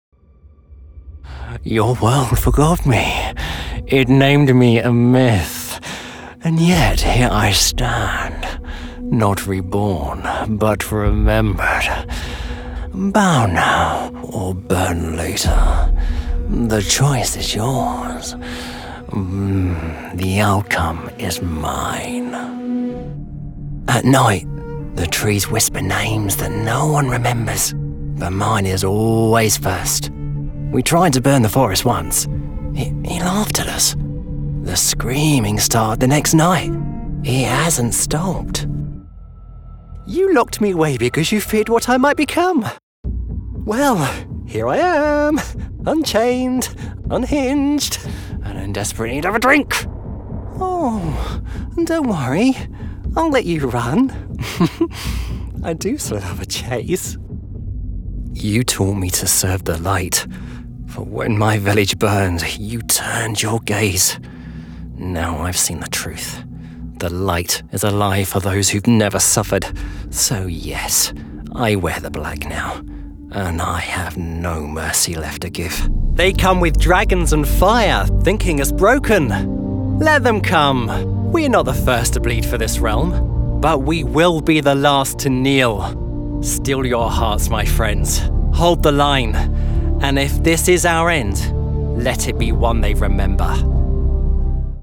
Kommerziell, Natürlich, Unverwechselbar, Freundlich, Junge, Corporate
Persönlichkeiten
neutral British accent described as having a fresh, clear, measured and self-assured tone